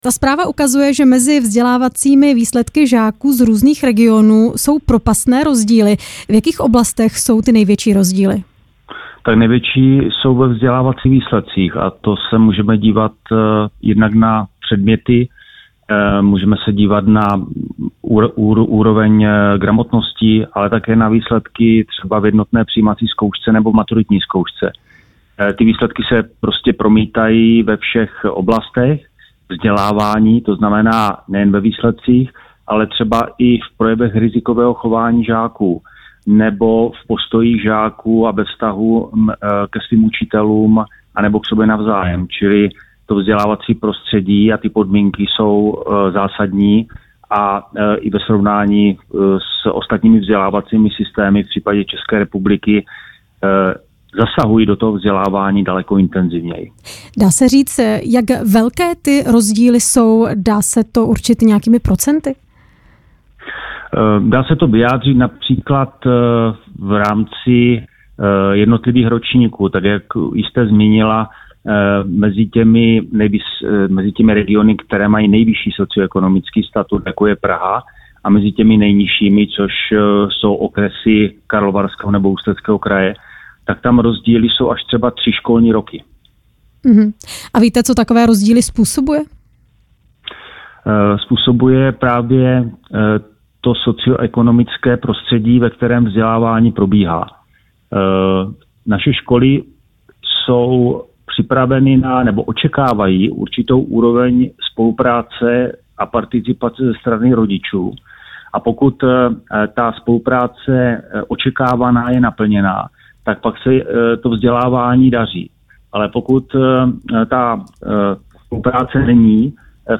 Zatímco žáci v Praze a Brně dosahují lepších výsledků, ti z Ústeckého nebo Karlovarského kraje zaostávají, a to až o několik let. O důvodech a možných řešeních této situace jsme ve vysílání RP mluvili s s ústředním školním inspektorem,Tomášem Zatloukalem.
Rozhovor s ústředním školním inspektorem Tomášem Zatloukalem